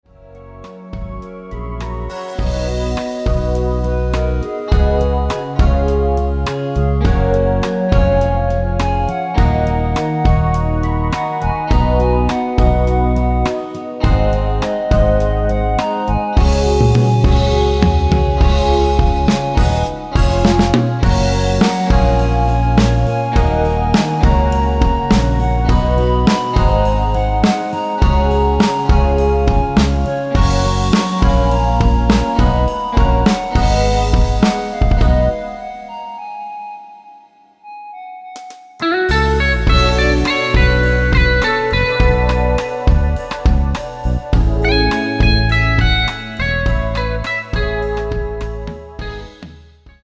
원키에서(+5)올린 멜로디 포함된 MR입니다.(미리듣기 확인)
F#
앞부분30초, 뒷부분30초씩 편집해서 올려 드리고 있습니다.
중간에 음이 끈어지고 다시 나오는 이유는